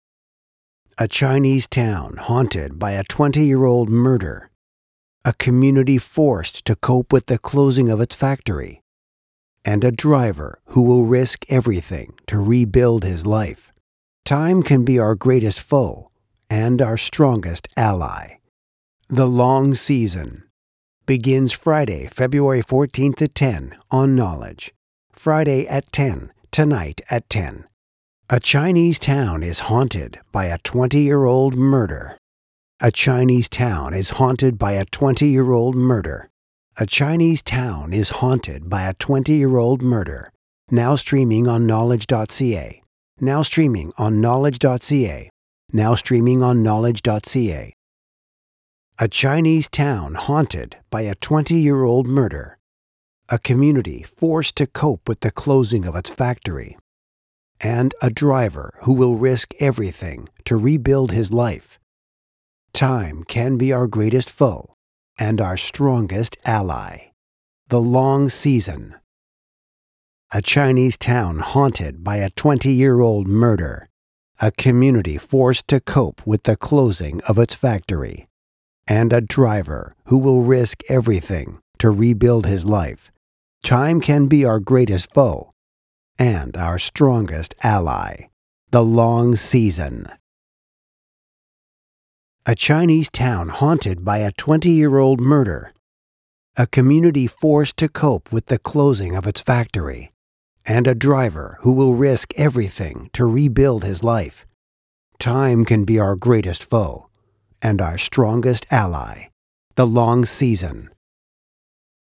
TV Promo Dark Crime drama